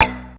4. 04. Percussive FX 03 ZG